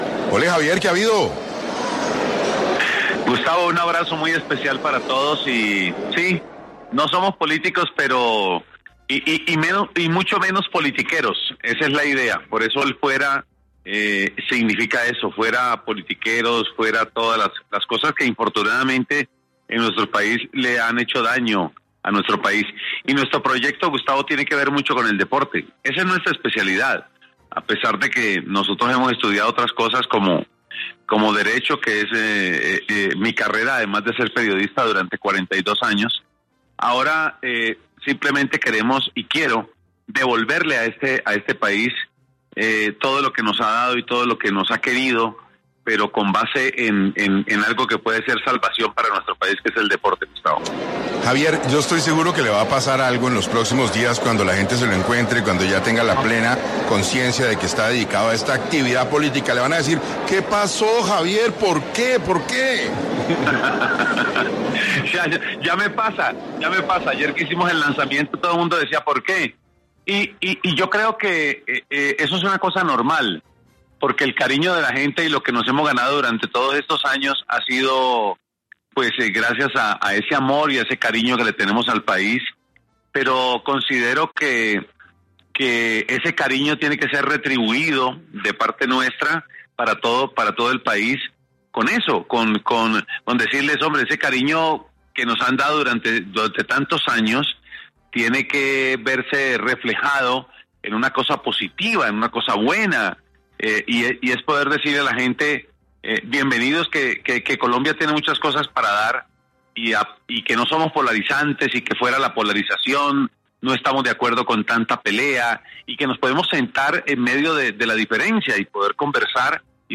En entrevista con 6AM de Caracol Radio, el cantante del gol, aseguro que su movimiento no es de políticos y mucho menos de politiqueros.